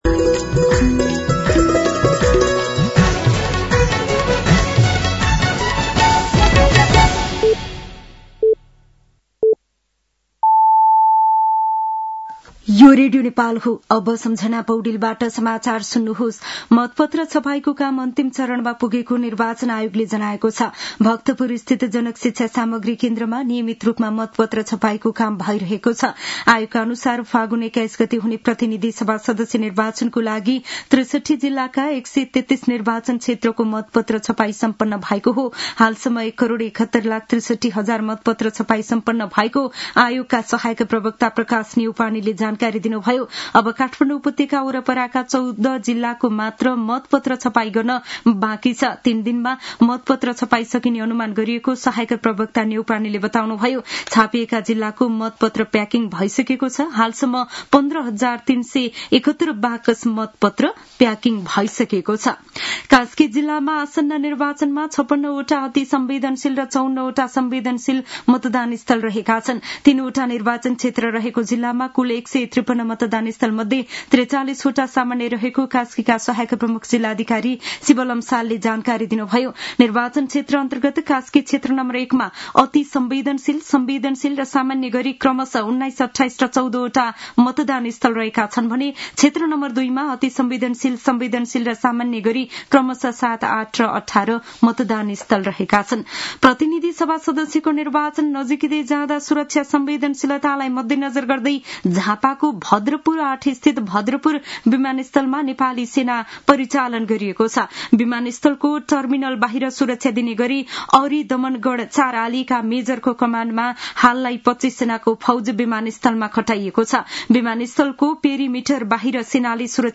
साँझ ५ बजेको नेपाली समाचार : २ फागुन , २०८२
5.-pm-nepali-news-1-7.mp3